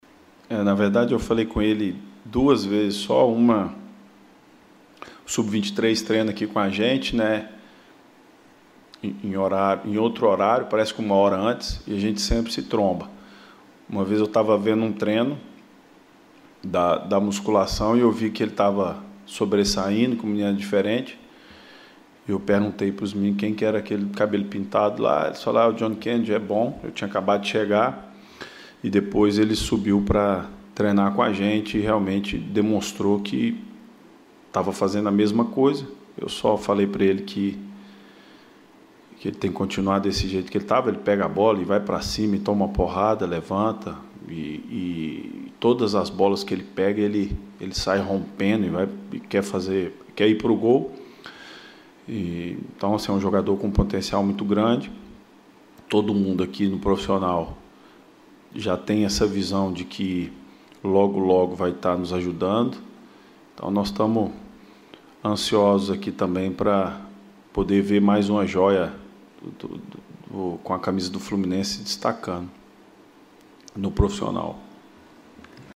O atacante participou da videoconferência desta terça-feira (05.01) com os jornalistas e falou sobre este tema e outros assuntos importantes.